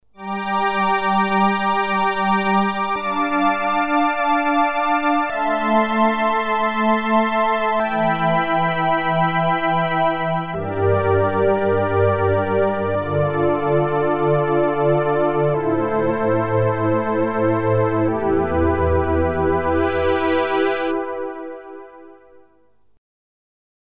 Lush pads, chords played at different octaves, filter mod by joystick at the end.
Unease_Juno-106_pads.mp3